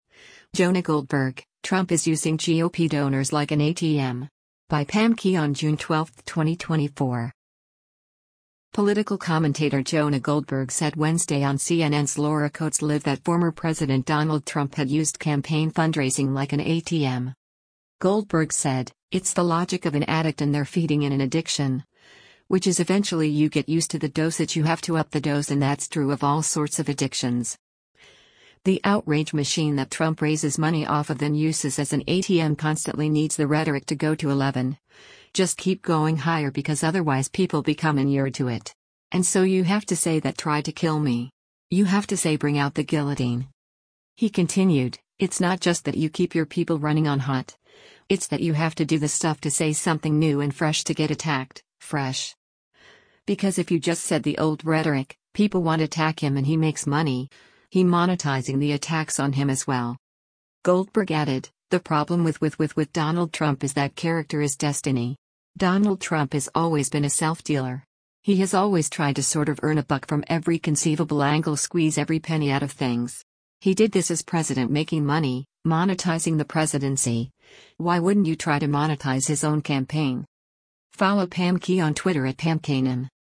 Political commentator Jonah Goldberg said Wednesday on CNN’s “Laura Coates Live” that former President Donald Trump had used campaign fundraising like an ATM.